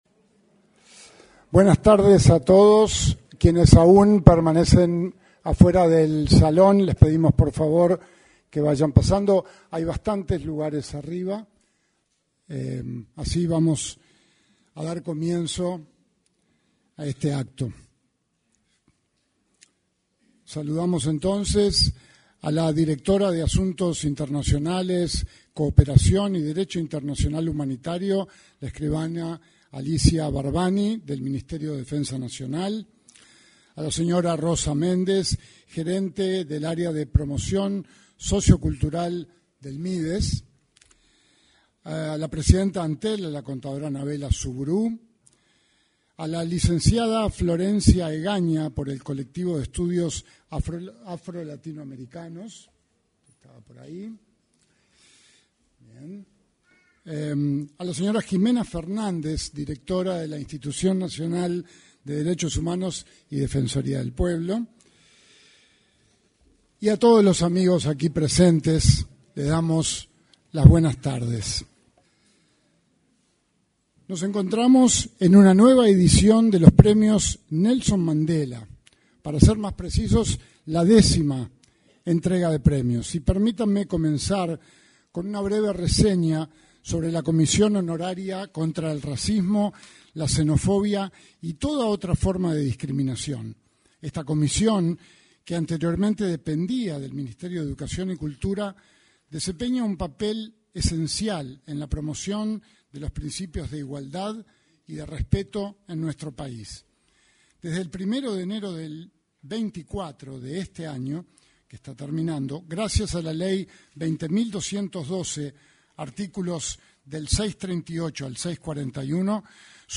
En el salón de actos de Torre Ejecutiva se realizó la ceremonia de entrega de los Premios Nelson Mandela.